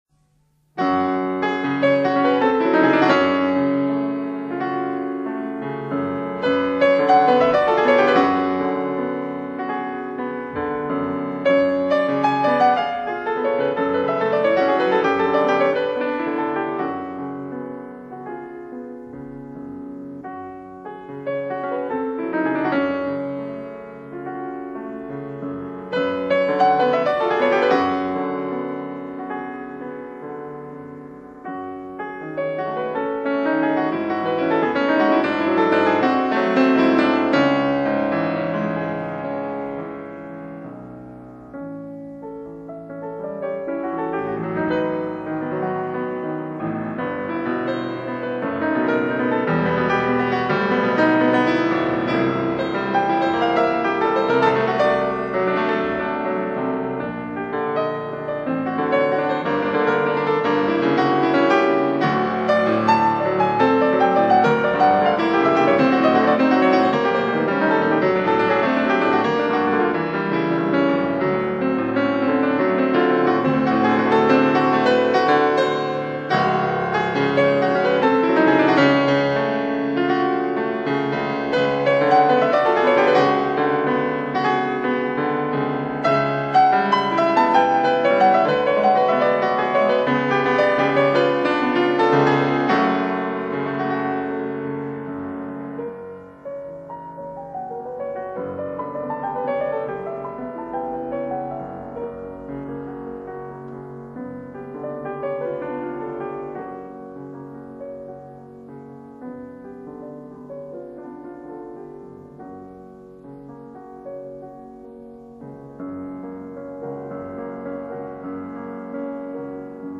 세번째 앵콜곡/쇼팽-폴로네이즈 6번 영웅(Heroique) /타마시 바샤리 연주